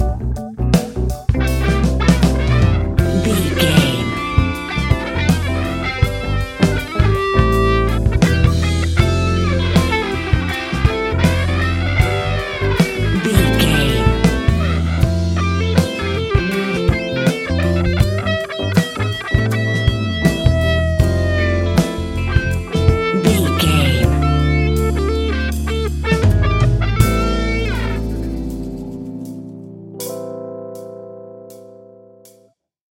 Epic / Action
Fast paced
In-crescendo
Uplifting
Ionian/Major
hip hop